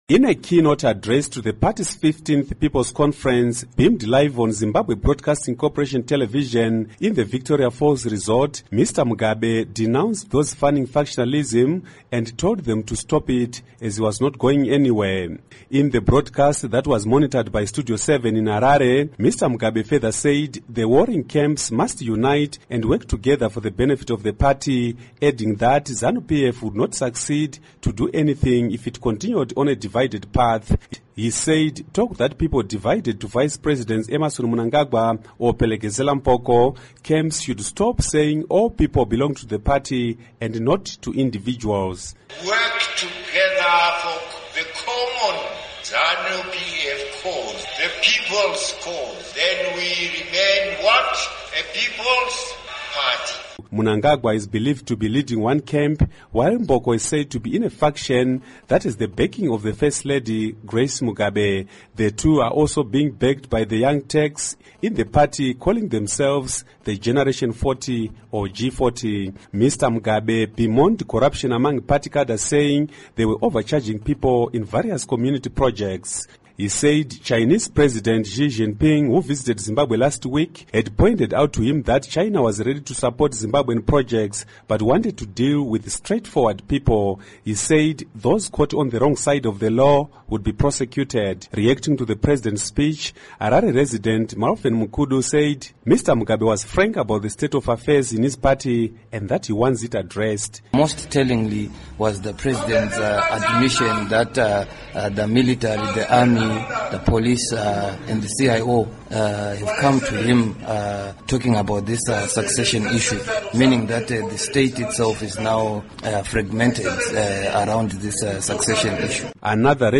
Report on Zanu PF Conference